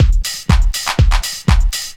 Detroit Beat 2_122.wav